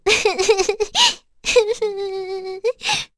Cecilia-Vox_Sad.wav